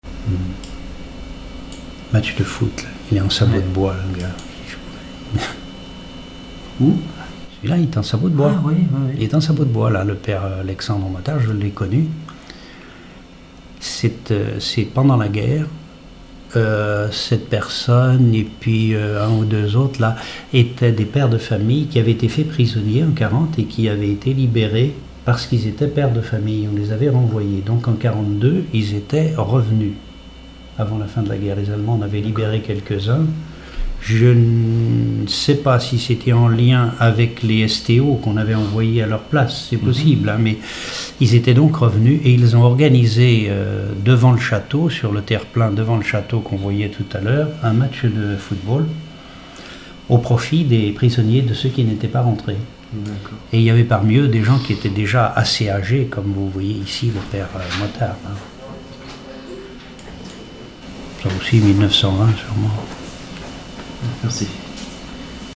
Témoignages ethnologiques et historiques
Catégorie Témoignage